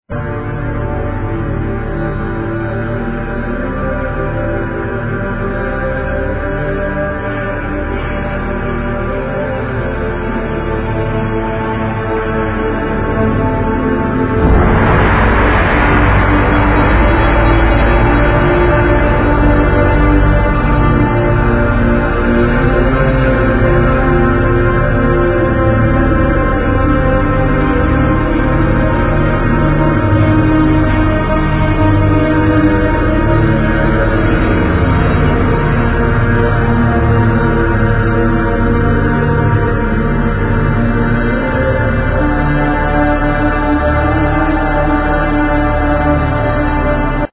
Bowed Cymbal, Trombone